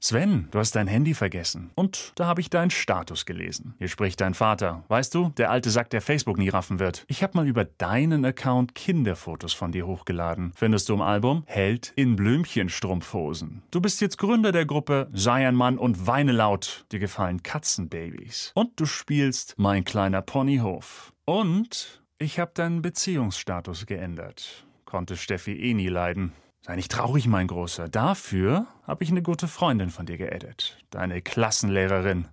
vielseitig, vertrauensvoll, sonor, jung, warm, markant, ausdrucksstark, authentisch, seriös, werbend, humorvoll, wiedererkennbar
Sprechprobe: Sonstiges (Muttersprache):